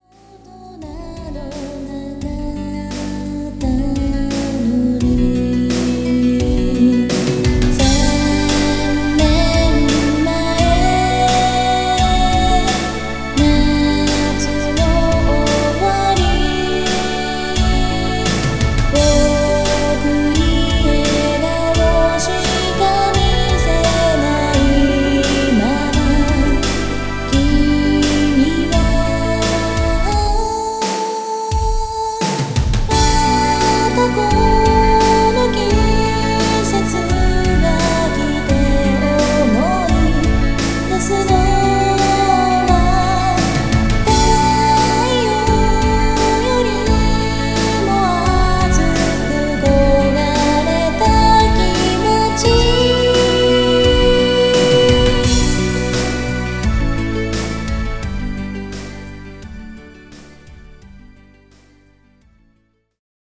オケも本作頒布にあたって新規に収録しなおしました。